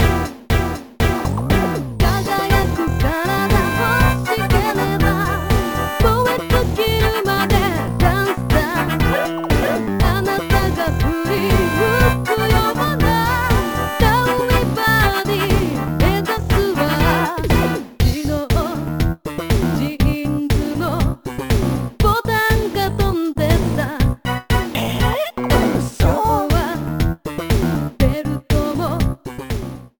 Trimmed and fade-out
Fair use music sample